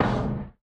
foundry-slide-stop.ogg